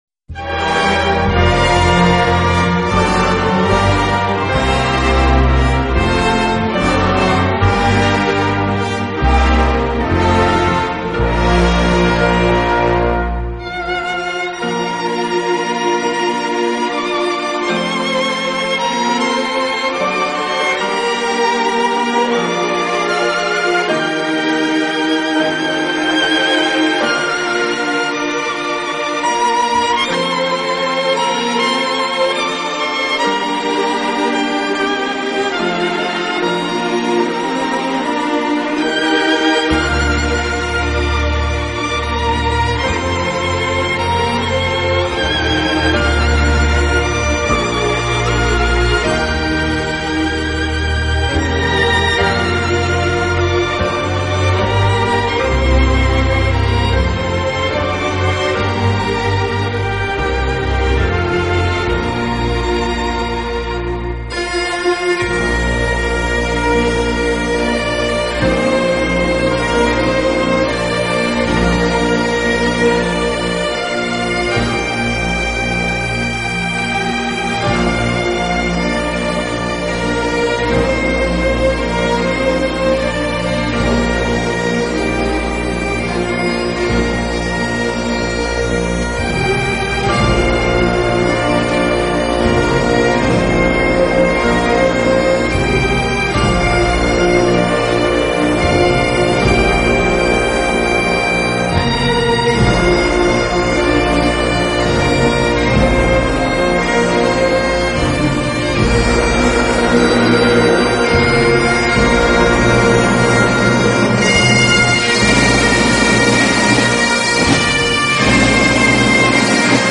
类型：Classical
他和自己的小型管弦乐队默契配合，将古典音乐与大众音乐进行了有机的融合，